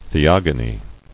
[the·og·o·ny]